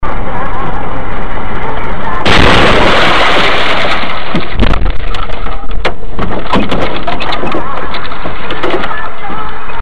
Звуки автомобильных аварий
Звук ДТП с видеорегистратора